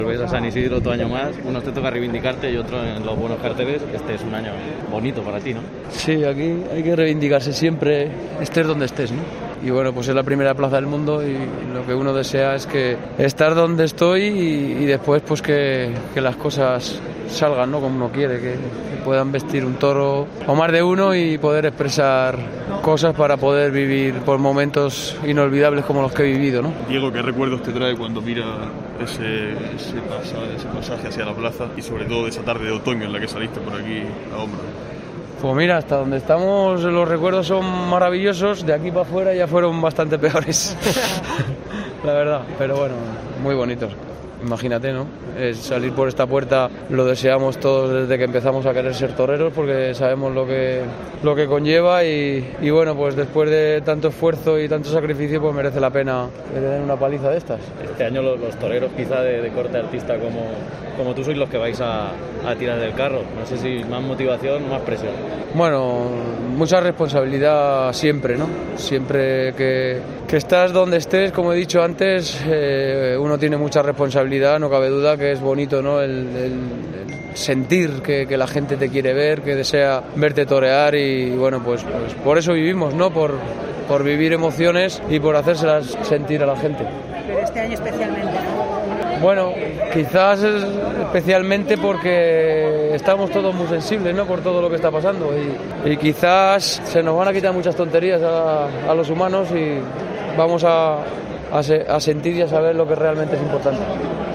El Albero de COPE habló con los principales protagonistas que acudieron a la gala de presentación de la Feria de San Isidro 2022